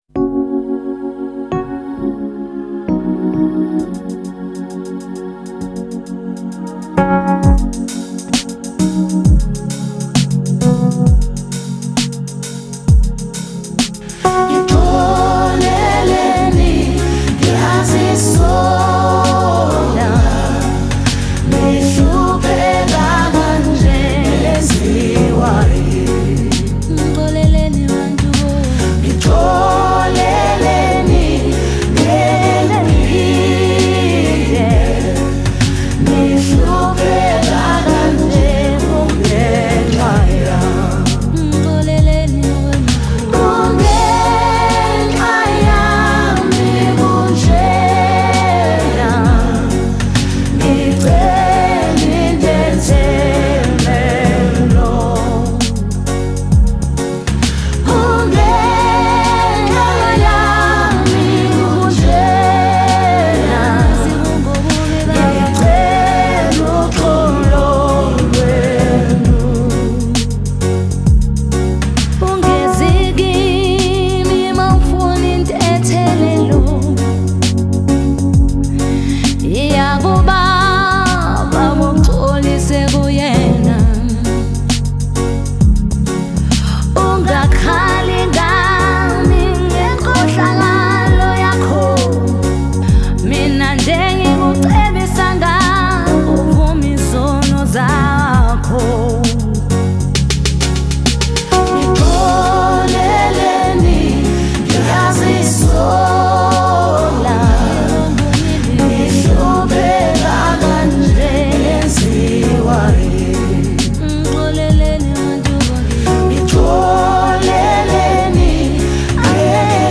January 30, 2025 Publisher 01 Gospel 0
was a South African gospel singer.